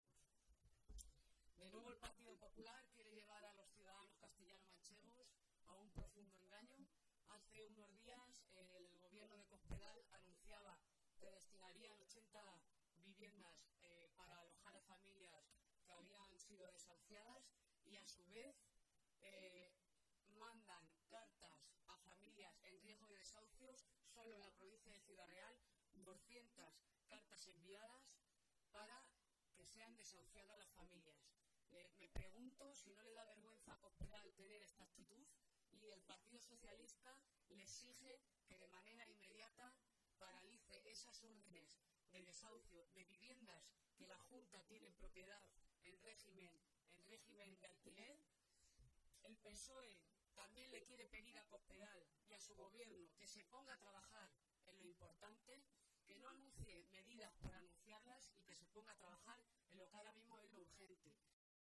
Momento de la rueda de prensa anterior a las jornadas